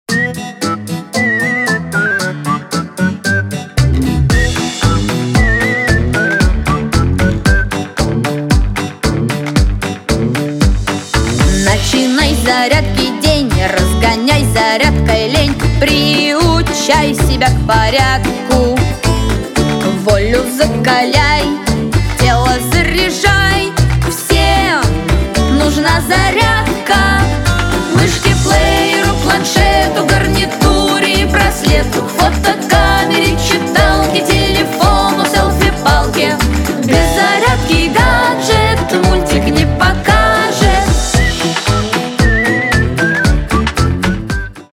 • Качество: 320, Stereo
веселые
детский голос
детские